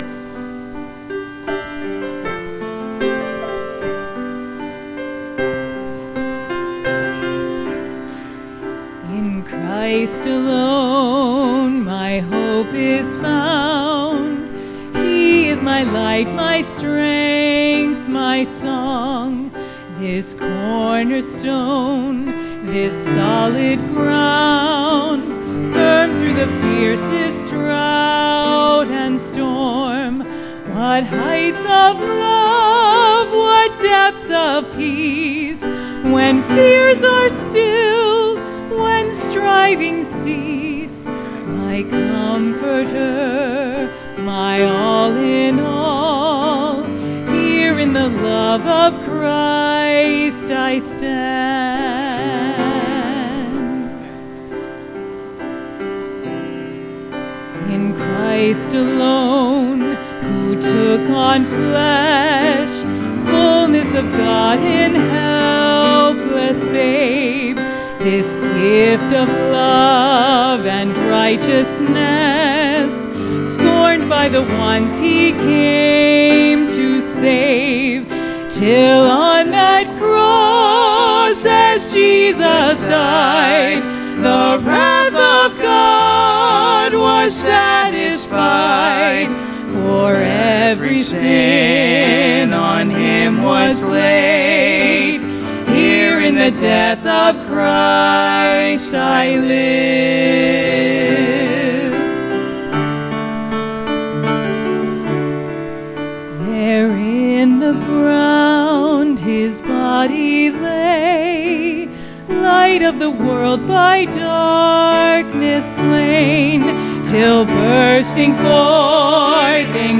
Sermons Archive • Fellowship Baptist Church - Madison, Virginia